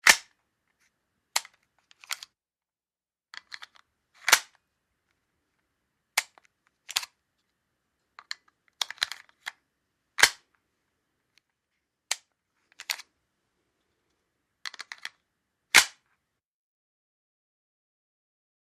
WEAPONS - HANDGUNS 9 MM: INT: Clip in & release, multiple takes, close up.